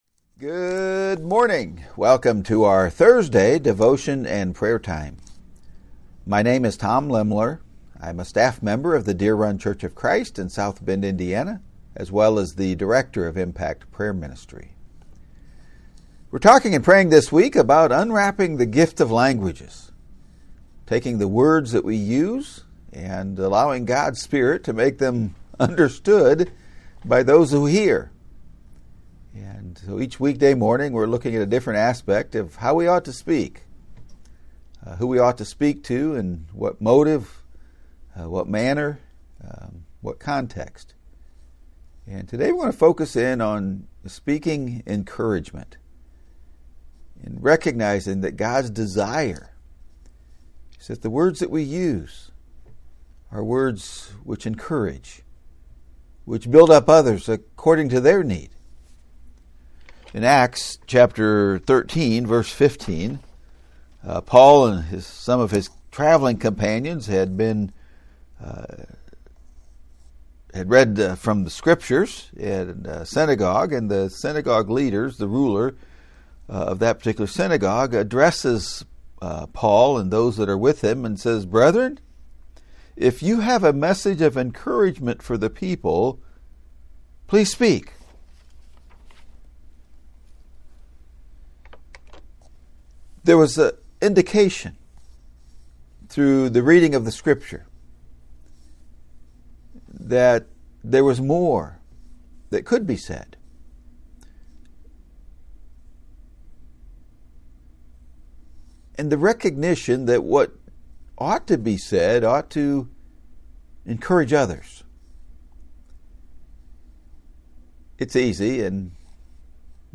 In prayer